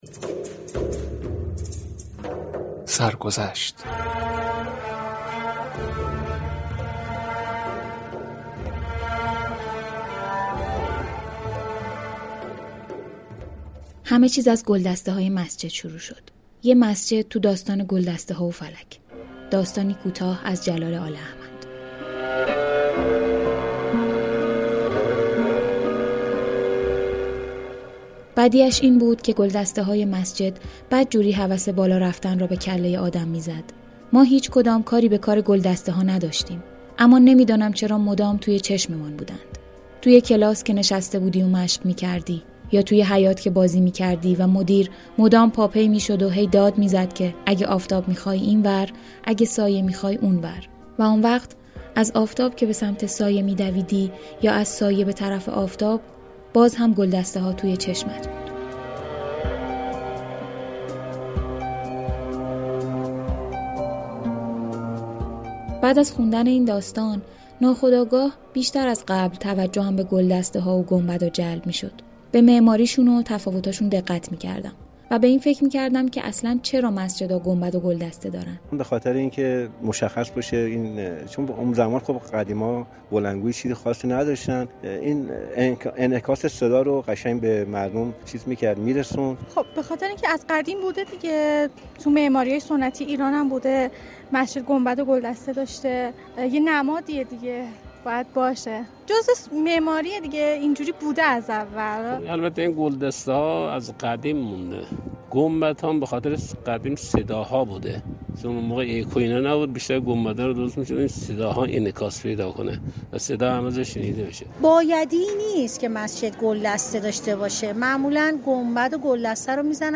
به گزارش روابط عمومی رادیو صبا، «سرگذشت» عنوان یكی از ویژه برنامه های این شبكه است كه به صورت مستند تقدیم مخاطبان می شود.